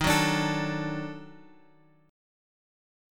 D#mM7b5 chord {x 6 x 7 7 5} chord